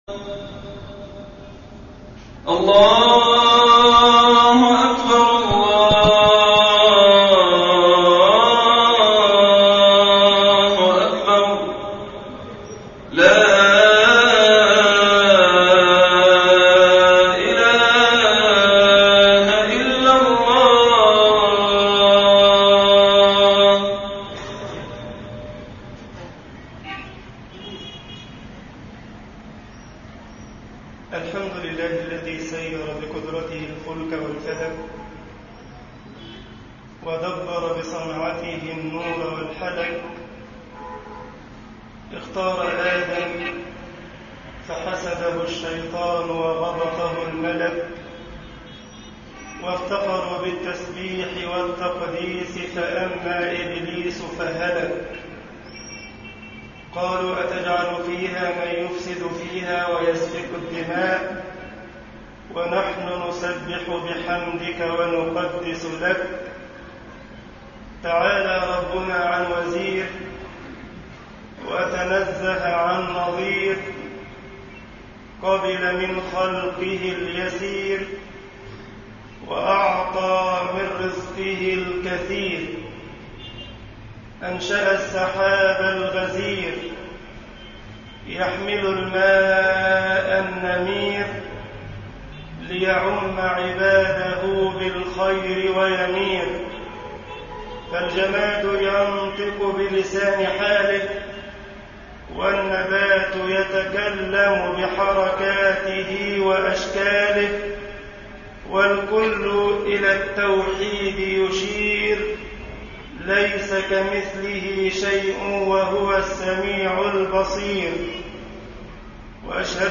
مسجد السلام أبو زارع ـ العمرانية الغربية ـ الجيزة خطبة الجمعة